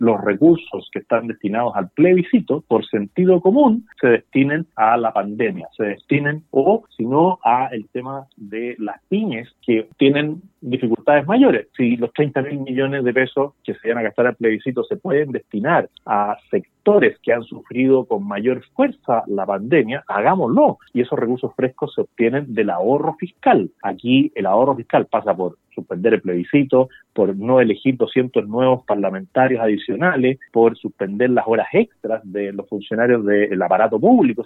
En entrevista con el programa Haciendo Ciudad de Radio Sago, el líder del Partido Republicano, José Antonio Kast, se refirió a la situación actual que vive hoy en día Chile en medio de la pandemia por coronavirus.